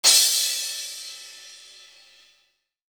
Crashes & Cymbals
CRASH100.WAV